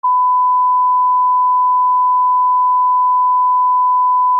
1khz.mp3